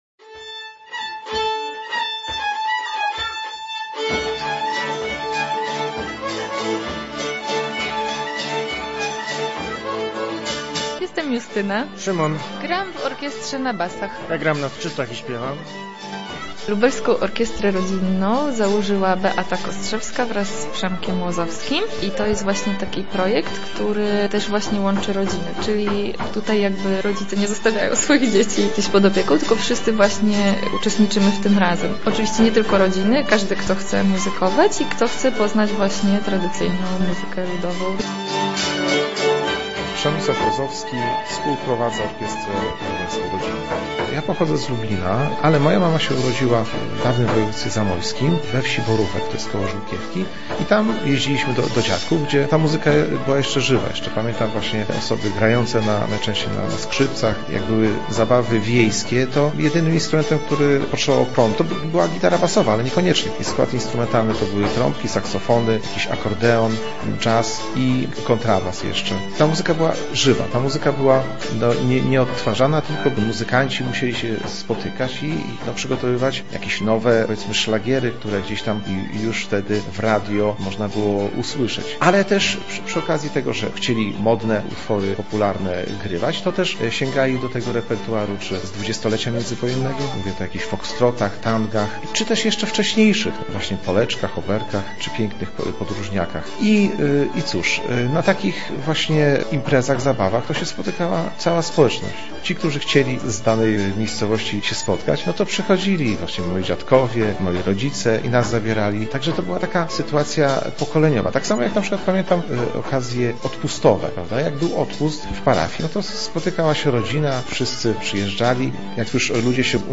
O to jak podtrzymywać i przekazywać dalej muzykę ludową, zapytaliśmy uczestników przedsięwzięcia.